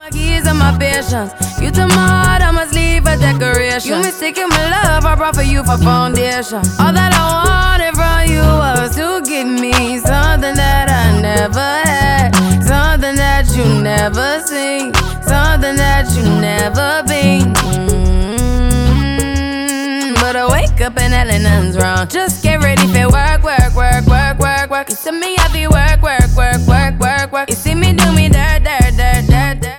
• Pop